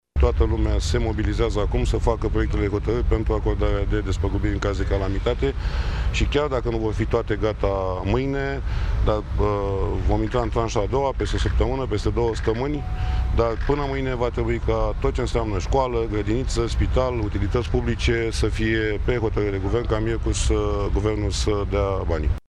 Declarația a fost făcută în urmă cu puțin timp de premierul Mihai Tudose care s-a deplasat la Timișoara pentru a participa la Comandamentul de Urgență împreună cu ministru de Interne, Carmen Dan.